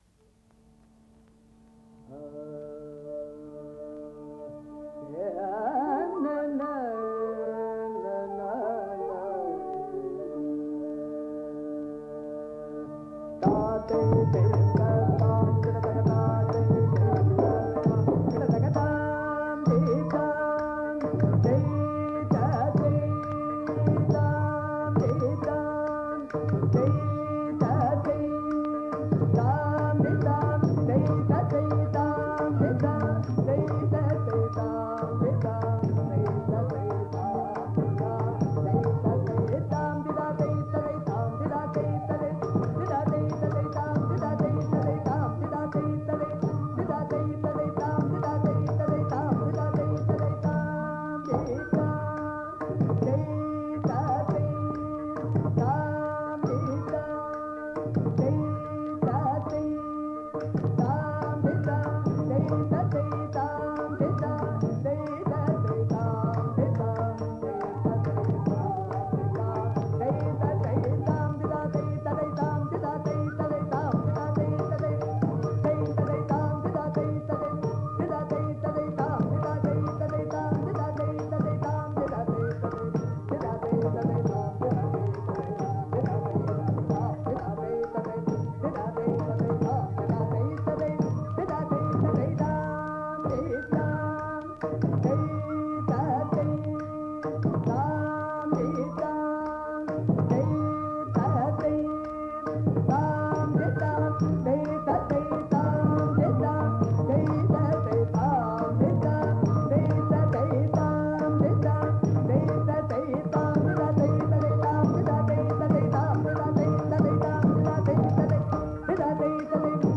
Bharatanatyam: Alarippu
Alarippu" in tiśra eka tāla.
The alarippu is organized into three main sections each of which is also divided into three sections. The musical principle in each of these sections is that of doubling the rhythmic density. The entire composition is framed by an introduction and a concluding cadential sequence.